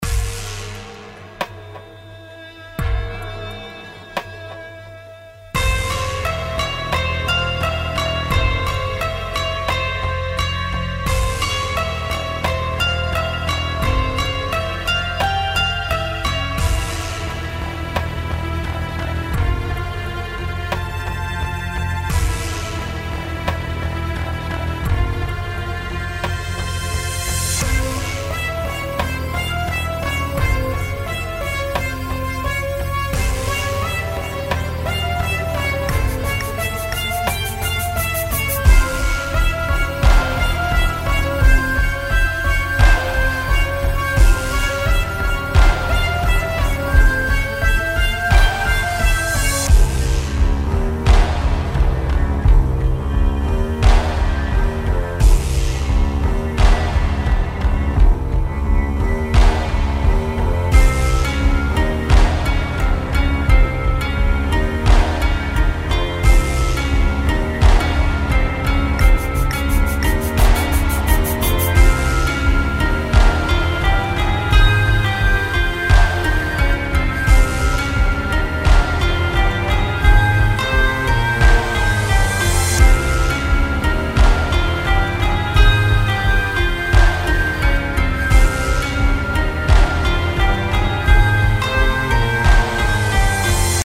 • スローなテンポ（84BPM)が、深く重たい空気感を演出
• アナログ感のあるパッド音源＋民族打楽器が静かに広がる
• リバーブとEQ処理で「深さ・奥行き・乾き」を表現
フリーBGM 孤独 砂漠 ゲームBGM アンビエント ファンタジー ミステリー 神秘 探検 静寂